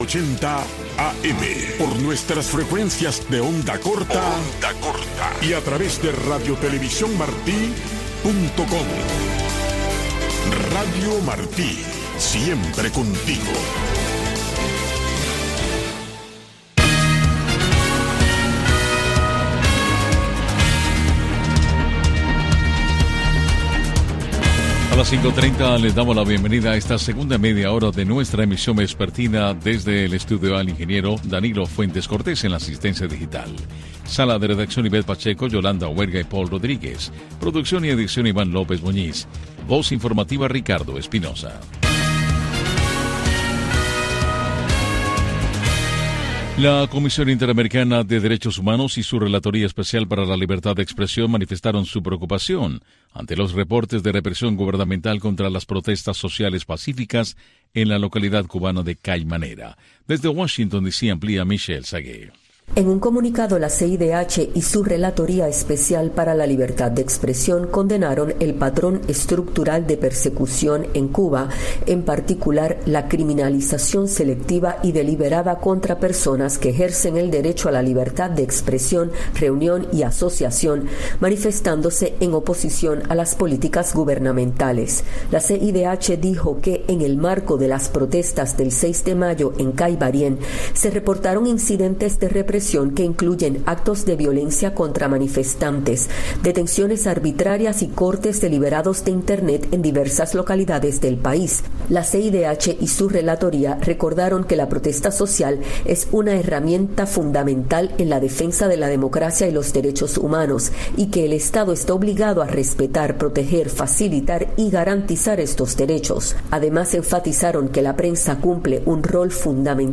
Noticiero Radio Martí presenta los hechos que hacen noticia en Cuba y el mundo